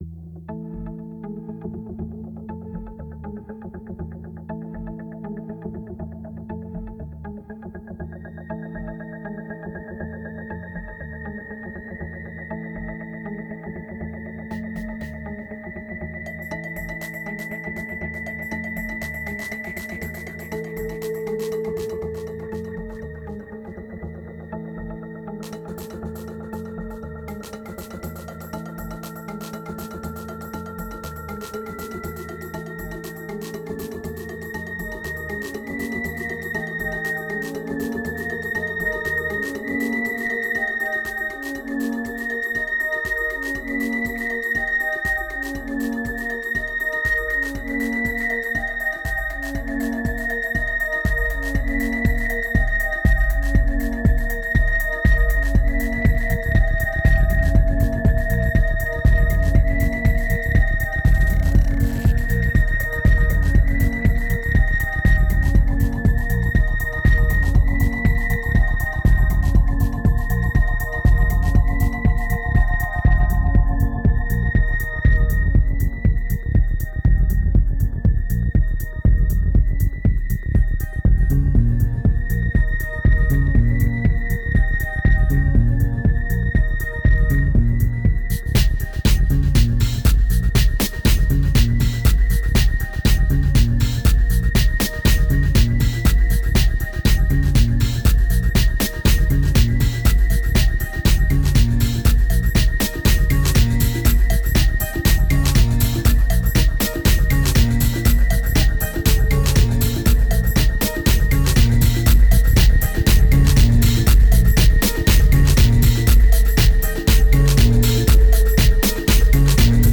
1980📈 - -51%🤔 - 120BPM🔊 - 2010-07-25📅 - -341🌟